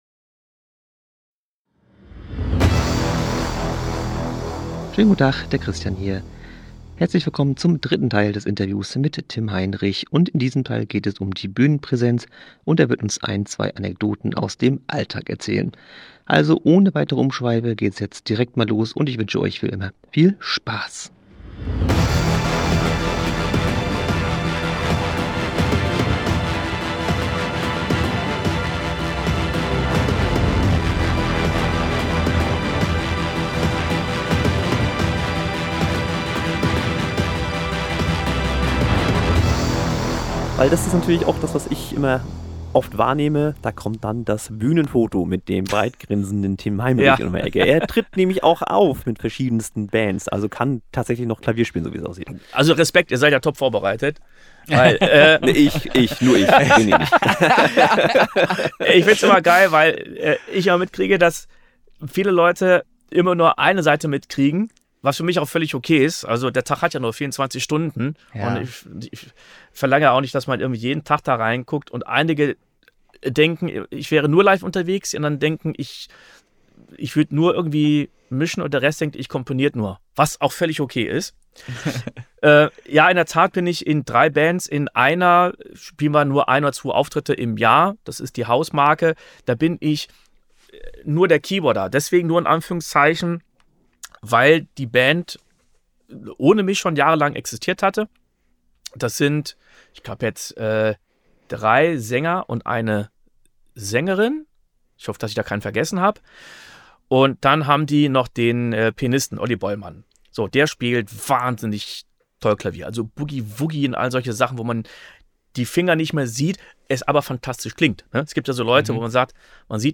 Letzte Episode Rauchende Colts und rauchende Synthesizer – Das Interview